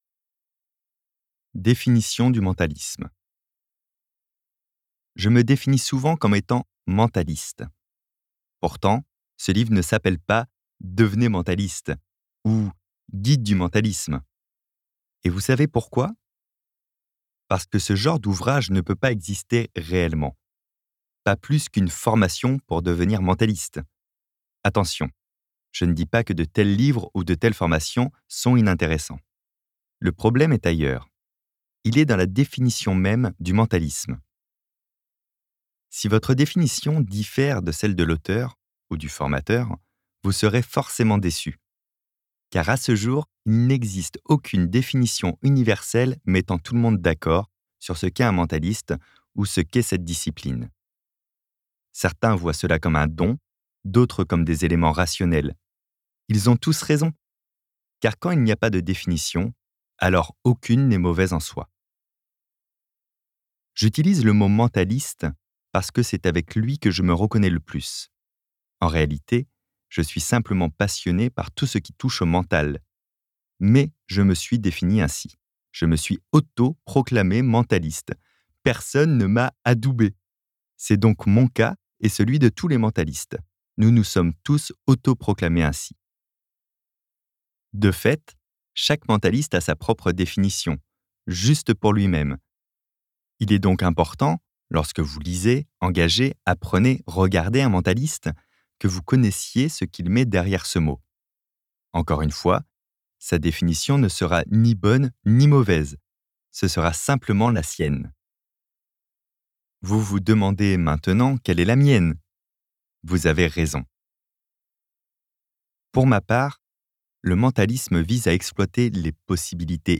copyparty md/au/audiobook/Fabien Olicard - Votre cerveau est extraordinaire